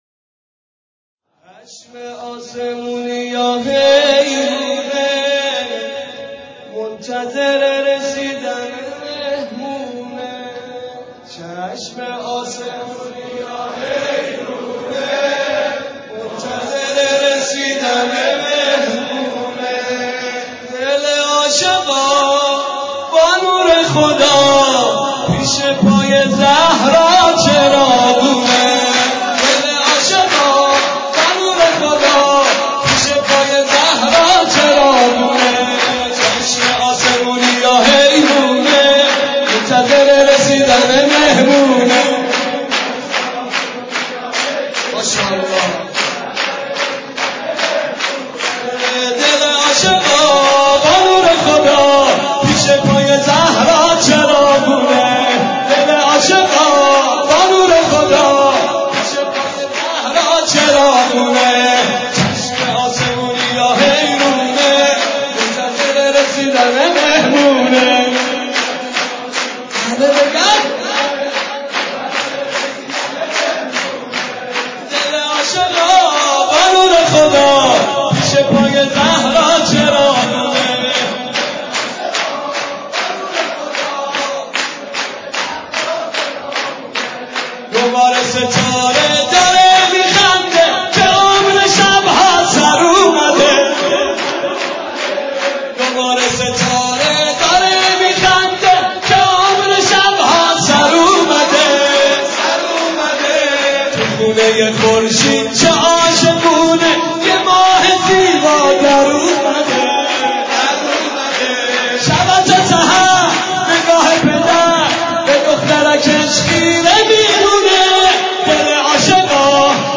شور ولادت حضرت زهرا(س).